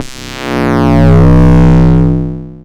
Mad Bass Sweep (JW3).wav